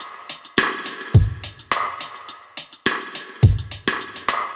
Index of /musicradar/dub-drums-samples/105bpm
Db_DrumKitC_Wet_105-03.wav